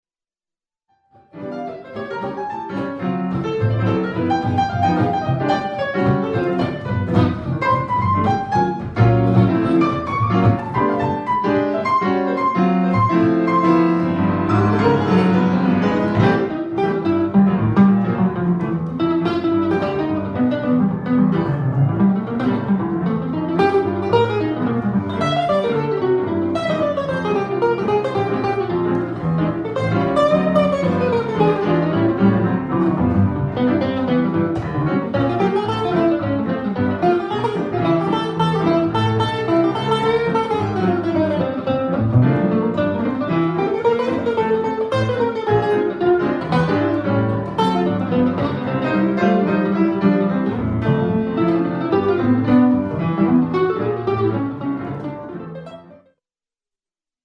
Festival Jazz à l'Heure d'Eté
guitare, chant
piano
contrebasse
batterie